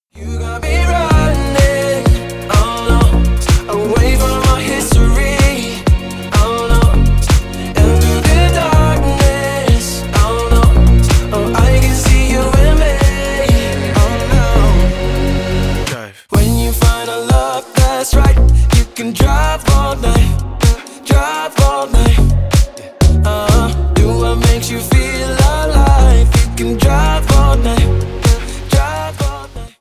Красивый женский голос
Dance pop Slap house
Танцевальные